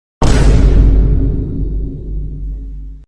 vine boom